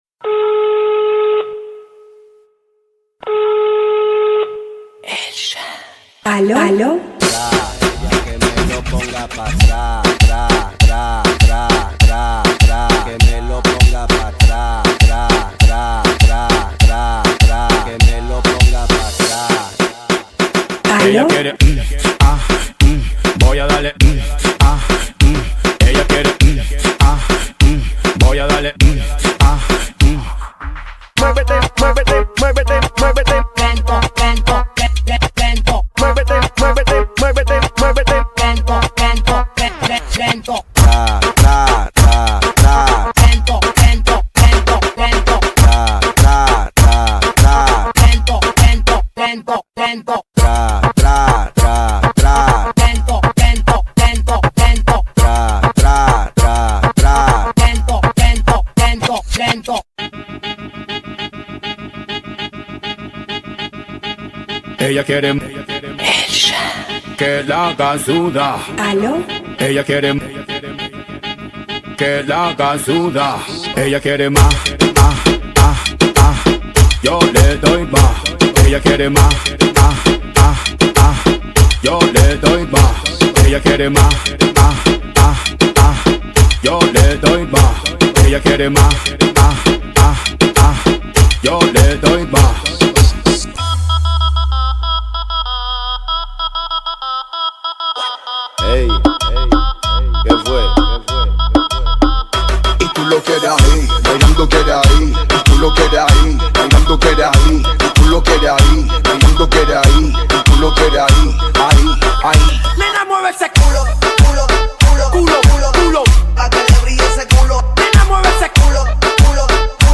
ریمیکس
دانلود ریمیکس شاد خارجی
مخصوص پارتی و جشن بترکونید
پیشنهاد ما مخصوص ماشین بیس بالا